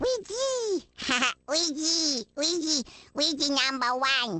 One of Baby Luigi's voice clips from the Awards Ceremony in Mario Kart: Double Dash!!